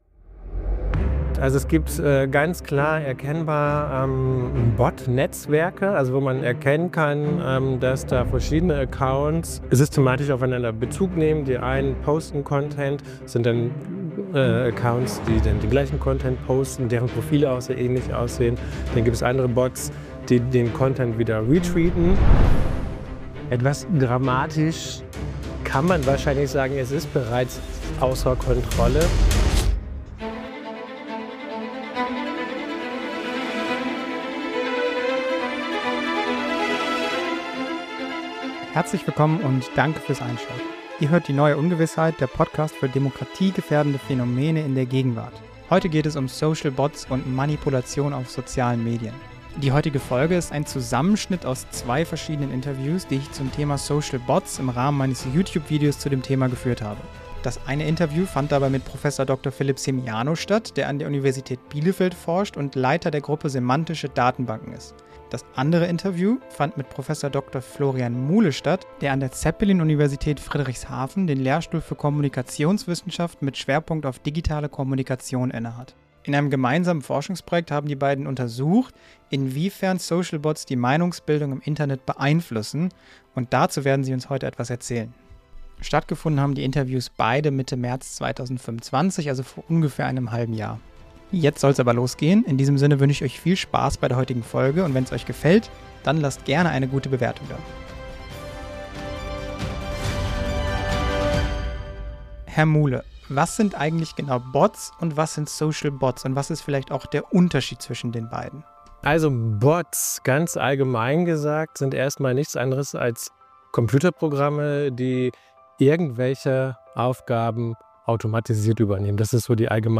Die beiden Interviews wurden für diese Folge zu einer Folge zusammengeschnitten. Wann immer es zu einem Sprecherwechsel kommt, wird dies durch eine erneute Namensnennung in der Frage verdeutlicht.